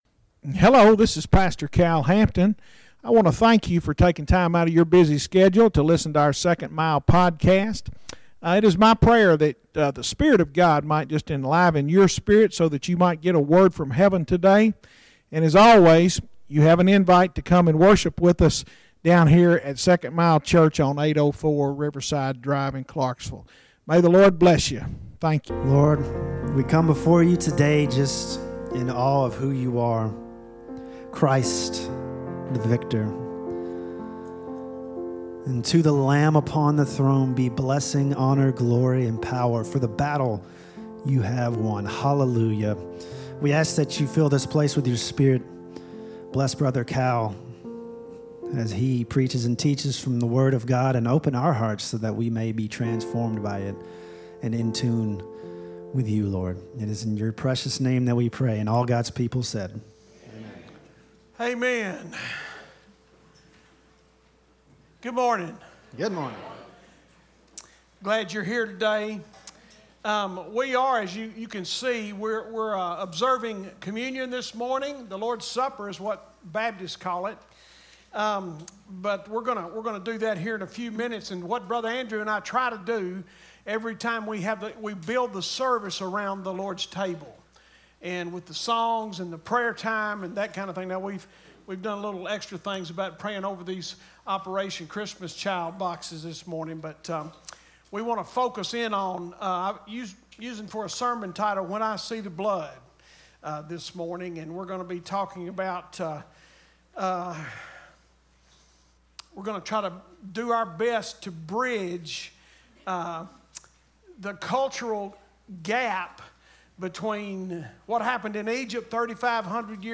Sermons Archive - 2nd Mile Church